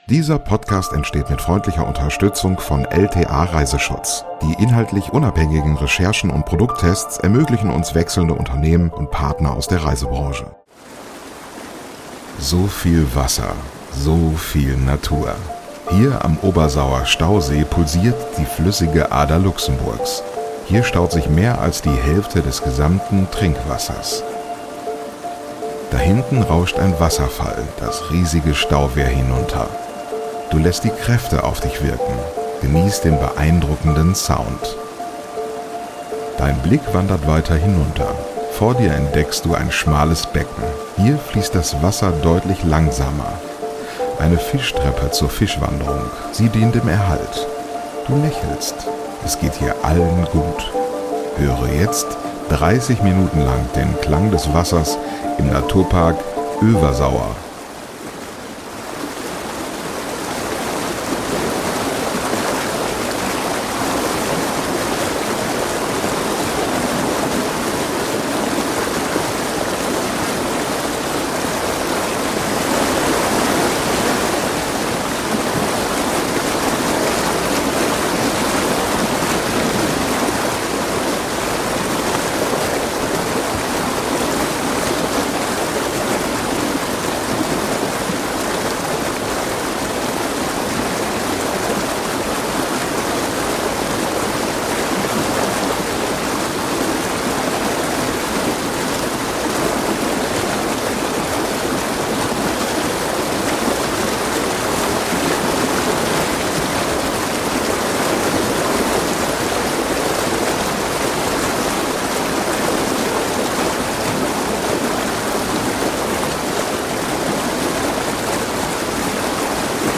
ASMR Fischtreppe in Luxemburg: Ambient 3D-Sound zum Einschlafen ~ Lieblingsreisen - Mikroabenteuer und die weite Welt Podcast
Hier am Obersauer-Stausee pulsiert die flüssige Ader Luxemburgs, hier staut sich mehr als die Hälfte des gesamten Trinkwassers. Da hinten rauscht ein Wasserfall das riesige Stauwehr hinunter, du lässt die Kräfte auf dich wirken, genießt den beeindruckenden Sound.
Dein Blick wandert weiter, hinunter, vor dir entdeckst du ein schmales Becken, hier fließt das Wasser deutlich langsamer.
Höre jetzt 30 Minuten lang den Klang des Wassers im Naturpark Öewersauer.